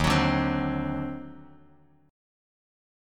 D#mM13 chord